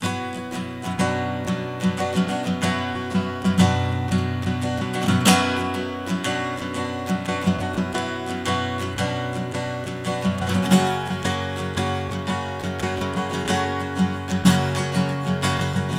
现场原声吉他墨西哥小调1 120bpm
描述：Fender原声吉他riff（120BPM）。
Tag: 120 bpm Classical Loops Guitar Acoustic Loops 2.69 MB wav Key : Unknown